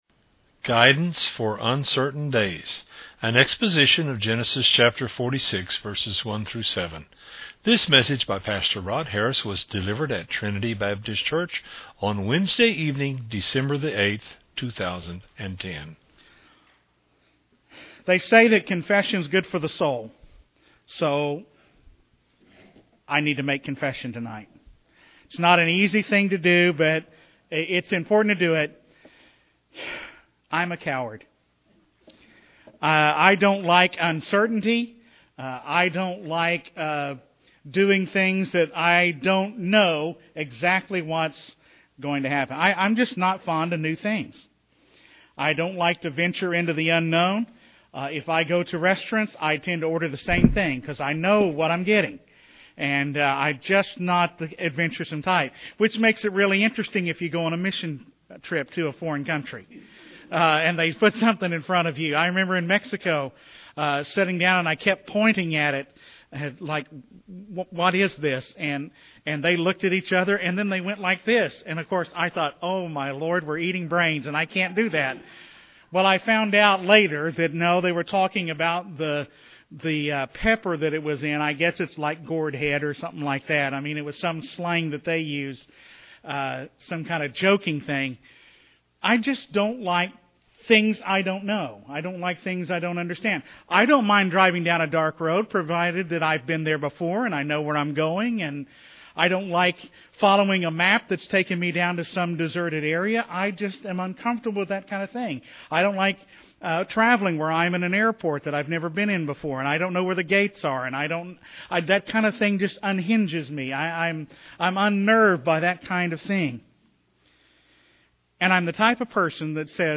Bible study
delivered at Trinity Baptist Church on Wednesday evening